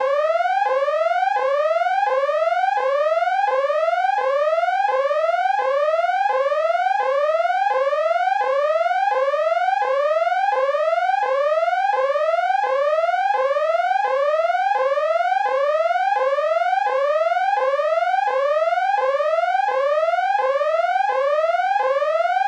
Звуки охранной сигнализации
Автомобильная охранная сигнализация